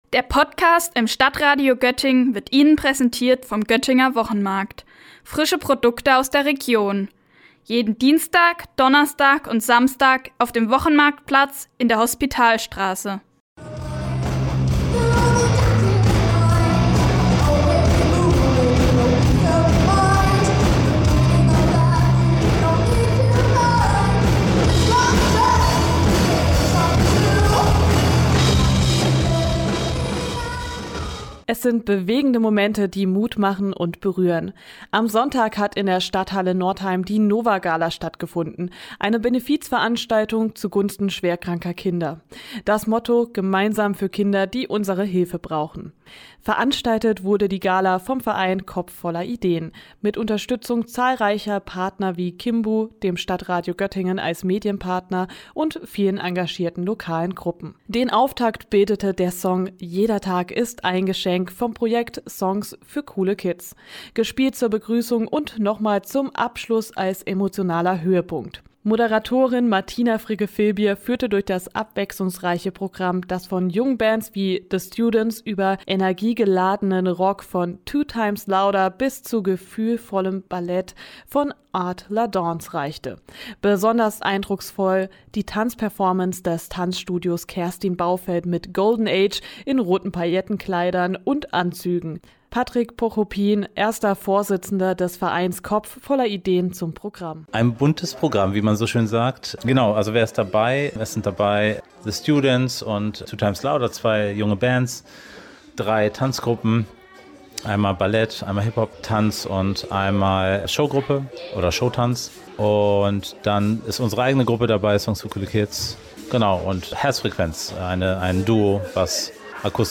Ein Sonntagnachmittag voller Musik, Tanz und Emotionen – in der Stadthalle Northeim stand alles unter einem Motto: „Gemeinsam für Kinder, die unsere Hilfe brauchen.“ Die NOVA-GALA bringt Menschen zusammen, um schwerkranken Kindern und ihren Familien Hoffnung zu schenken.